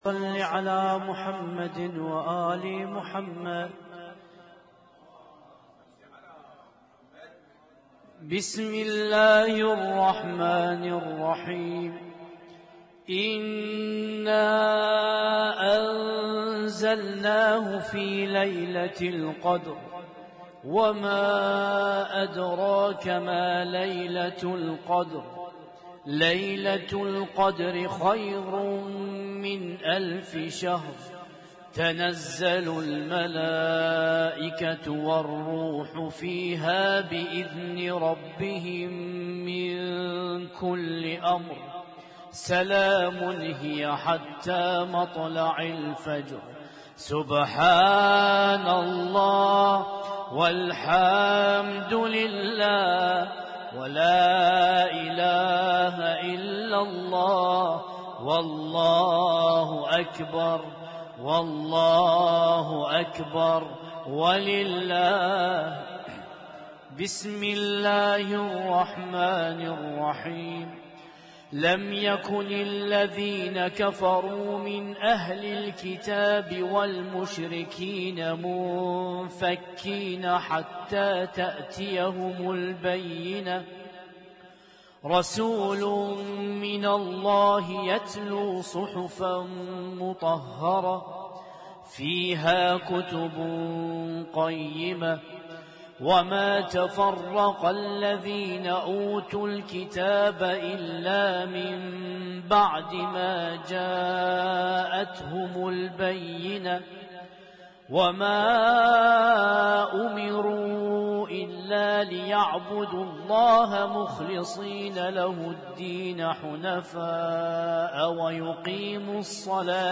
Husainyt Alnoor Rumaithiya Kuwait
اسم التصنيف: المـكتبة الصــوتيه >> القرآن الكريم >> القرآن الكريم - القراءات المتنوعة